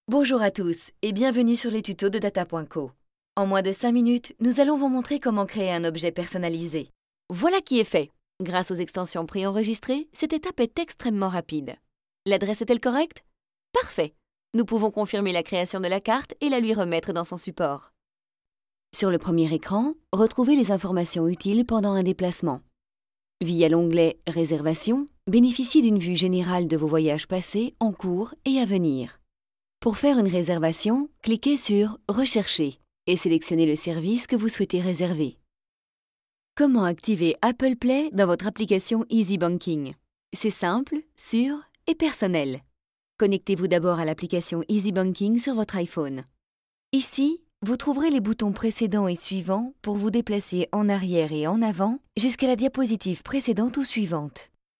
I like to modulate my voice according to the products.
Sprechprobe: eLearning (Muttersprache):
Professional actress without accent for more than 10 years!